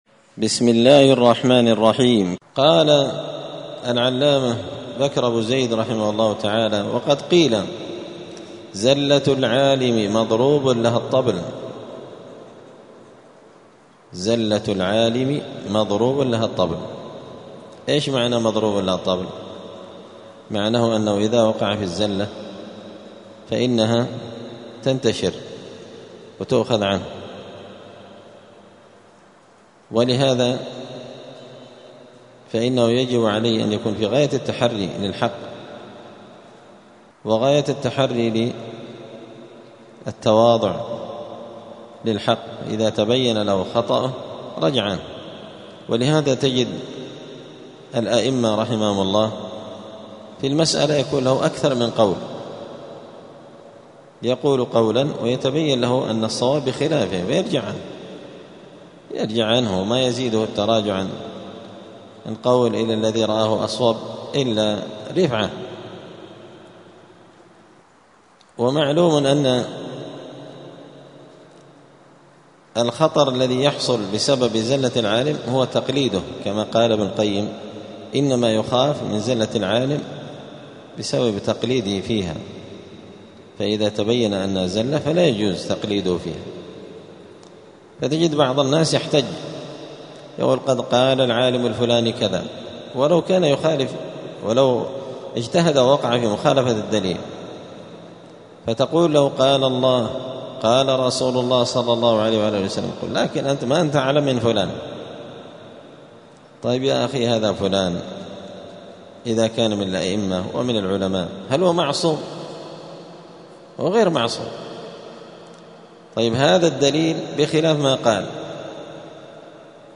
*الدرس الثالث (3) {فصل آداب الطالب في نفسه} (زلة العالم)*
دار الحديث السلفية بمسجد الفرقان قشن المهرة اليمن